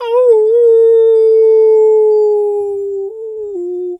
Animal_Impersonations
wolf_2_howl_long_05.wav